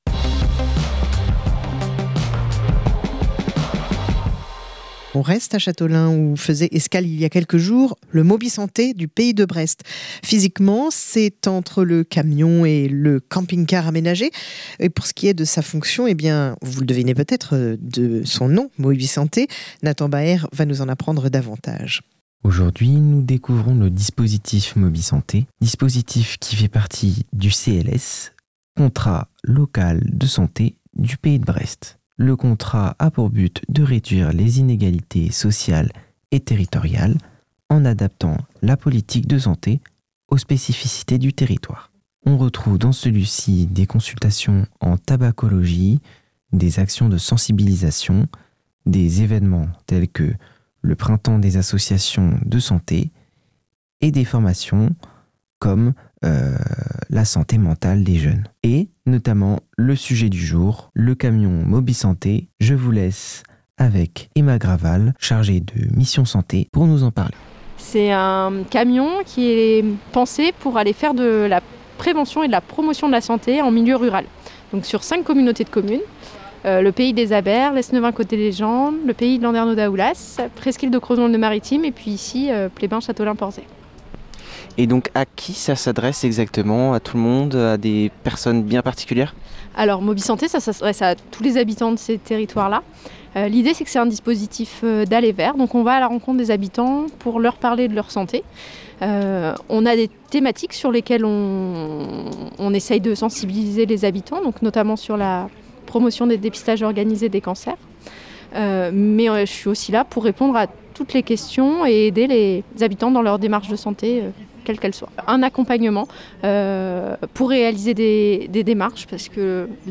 Aujourd'hui, c'est une escale à Châteaulin.